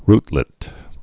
(rtlĭt, rt-)